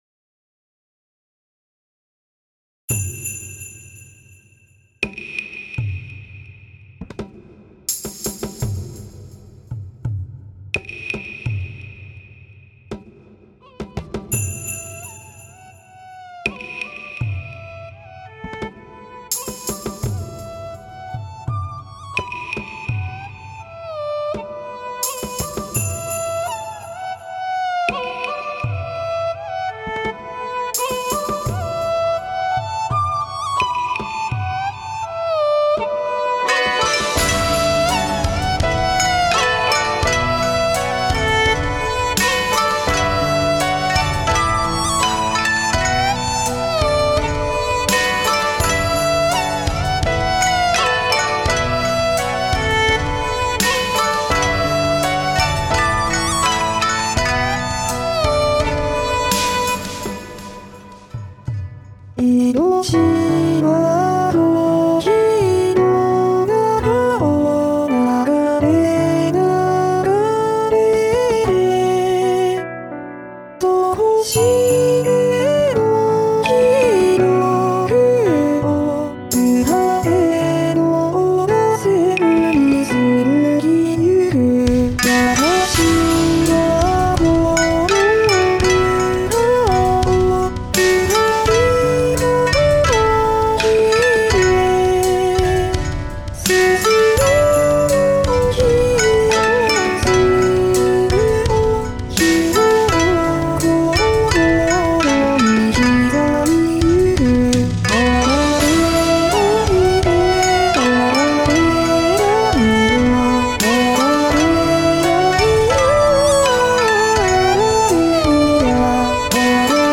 どうにも低音にするとレキになります。今回はg-1で歌わせてますが。